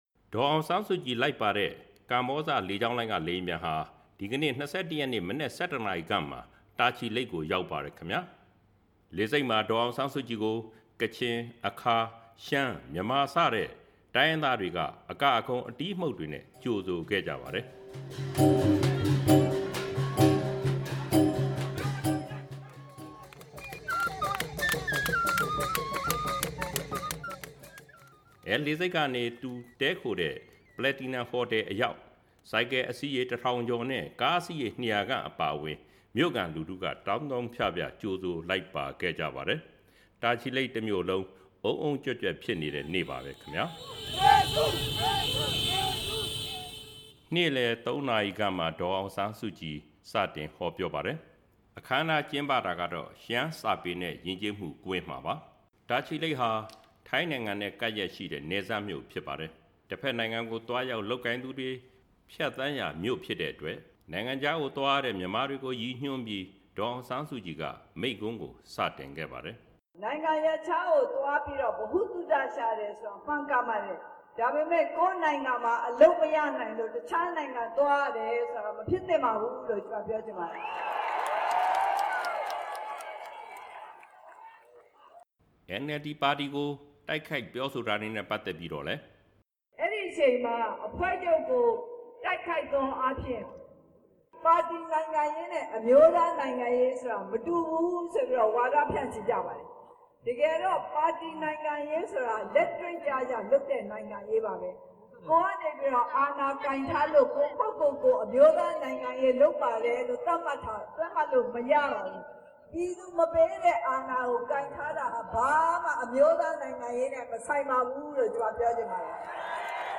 ဒေါ်အောင်ဆန်းစုကြည်ဟာ ဒီကနေ့ မနက် ၁၁ နာရီက ရှမ်းပြည်နယ် တာချီလိတ် မြို့ကို ရောက်ရှိပြီး နေ့လည် ၃ နာရီမှာ ဟောပြောပွဲကျင်းပပါတယ်။
ဒီပွဲကို လူသုံးထောင် တက်ရောက်ပါတယ်။ ဒေါ်အောင်ဆန်းစုကြည်ဟာ မနက်ဖြန် ကျိုင်းတုံမြို့ကို သွားရောက်ပါ လိမ့်မယ်။